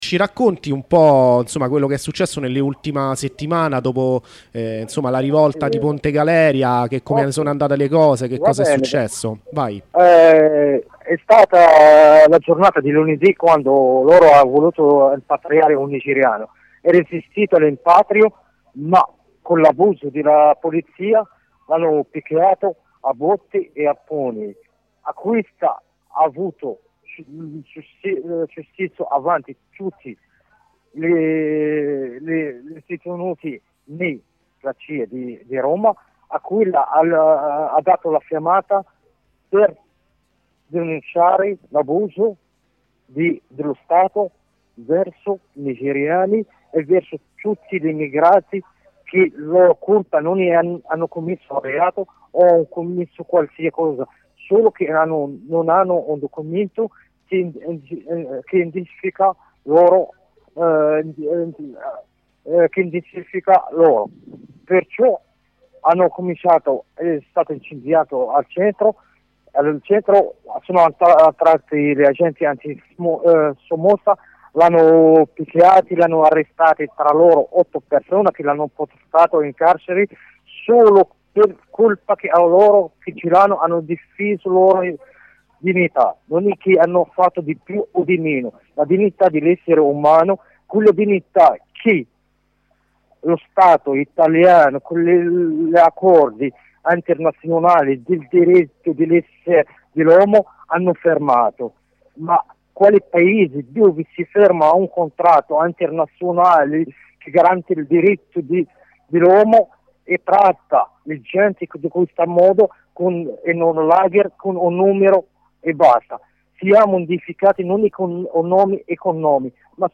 Corrispondenza con un compagno recluso nel CIE di Bari e li' deportato dopo le rivolte al CIE di Ponte Galeria a Roma